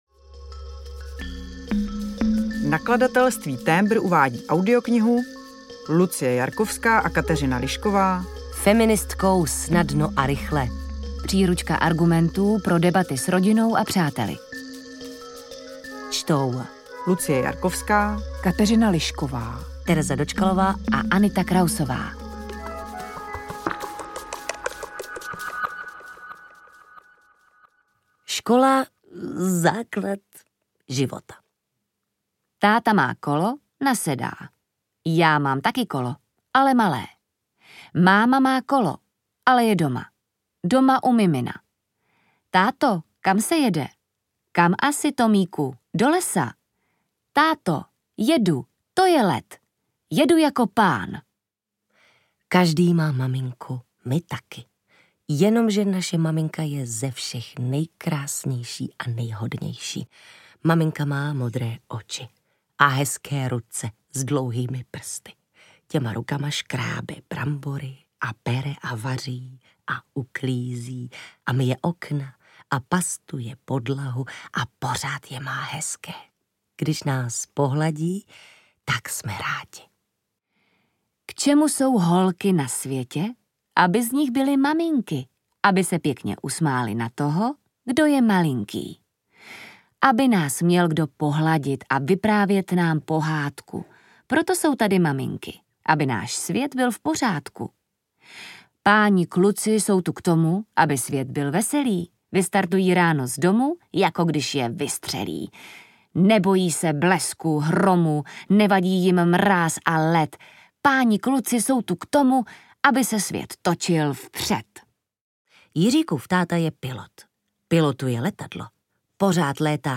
Ukázka z knihy
feministkou-snadno-a-rychle-audiokniha